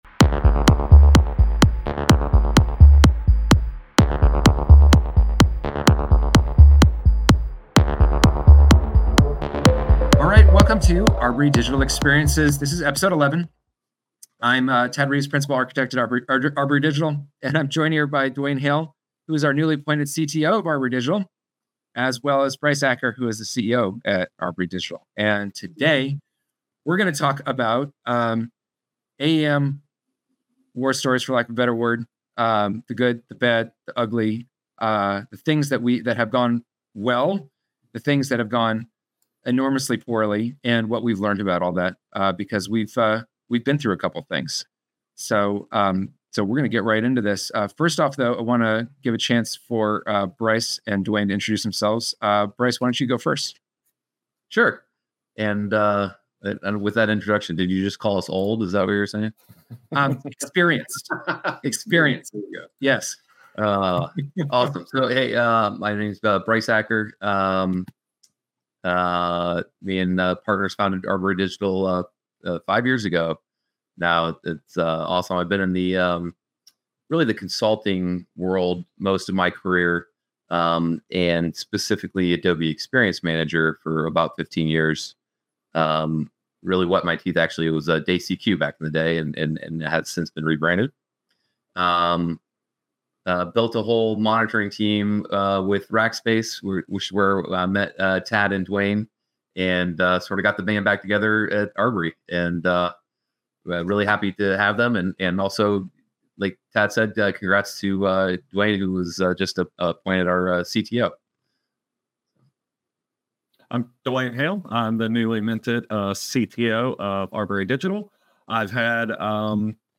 In this episode, 3 of our long-term AEM Architects trade war stories on implementations that went great, ones that went poorly, and ones that were barely-mitigated disasters.